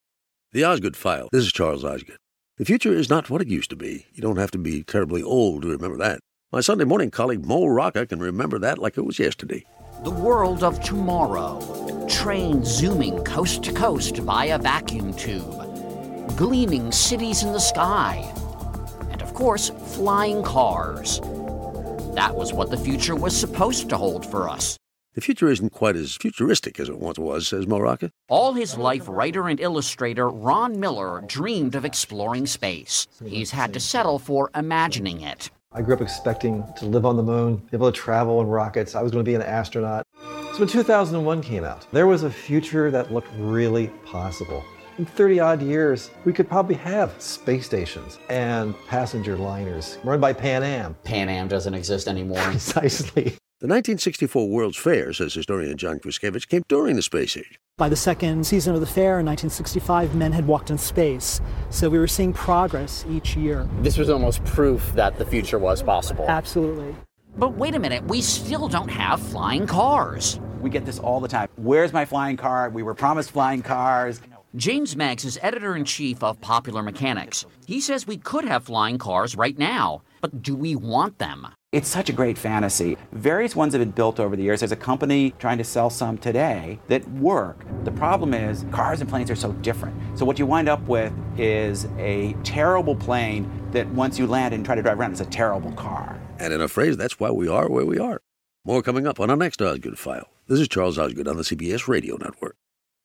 Charles Osgood, host of The Osgood File. Each Osgood File focuses on a single story, from major national news to a whimsical human interest vignette.